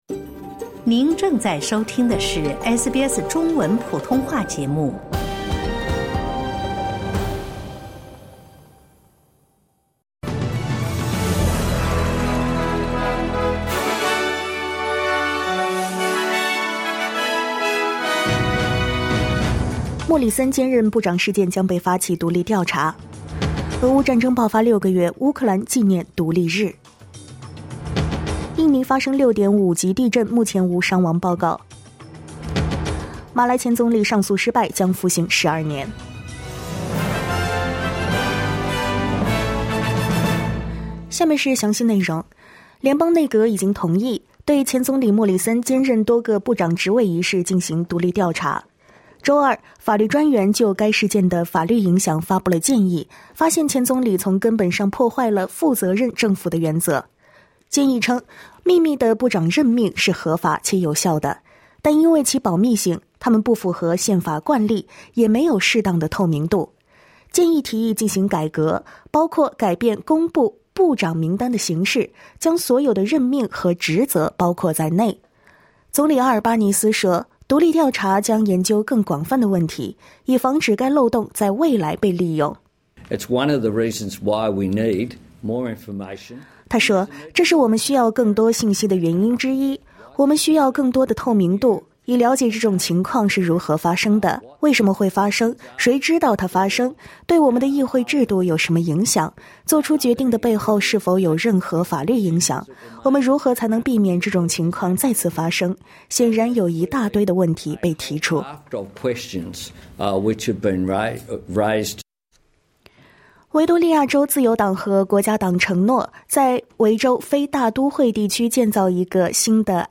SBS早新闻（8月24日）
请点击收听SBS普通话为您带来的最新新闻内容。